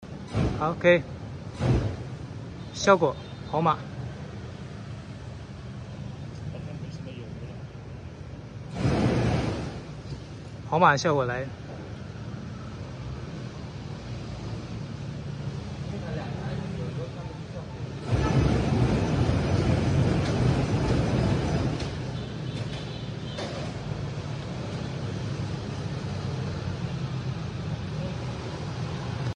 MOKA High flame machine PF 800, sound effects free download